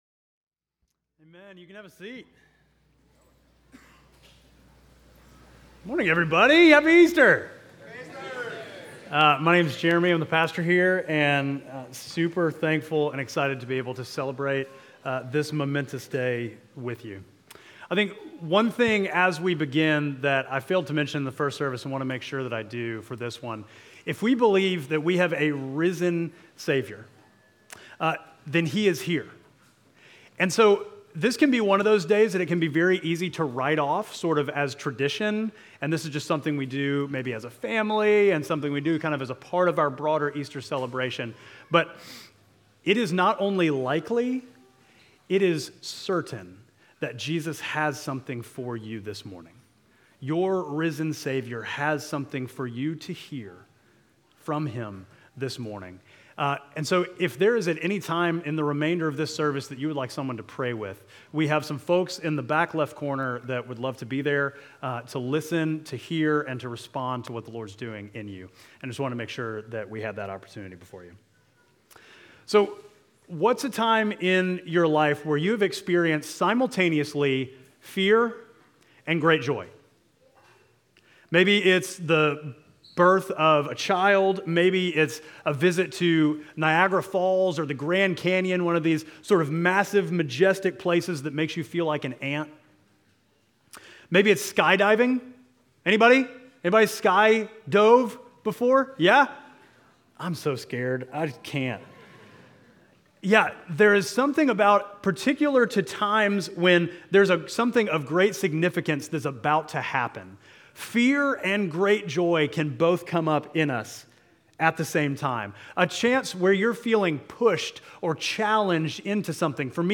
Midtown Fellowship Crieve Hall Sermons Easter Sunday: Fear and Great Joy Mar 31 2024 | 00:24:08 1x 00:00 / 00:24:08 Subscribe Share Apple Podcasts Spotify Overcast RSS Feed Share Link Embed